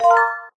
chime_1.ogg